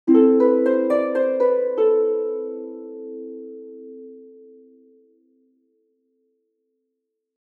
Harp 2.mp3